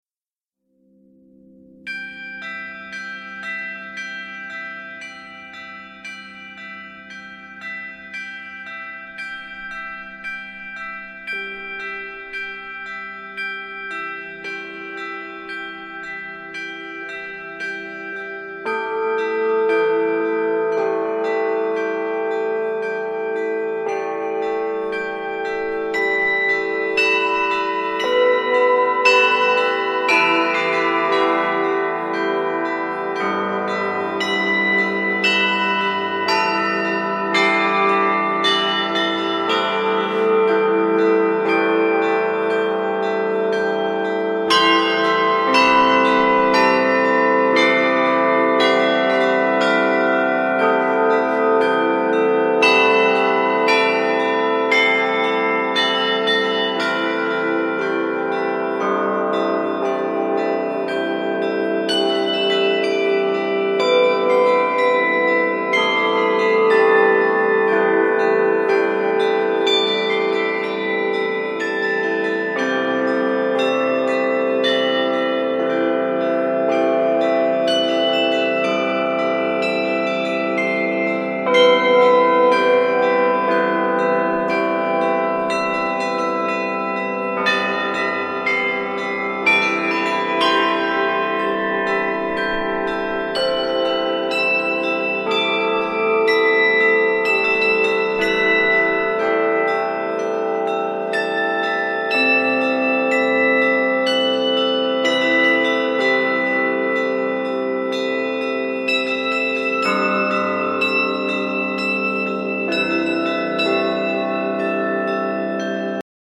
Колокольная медитация.mp3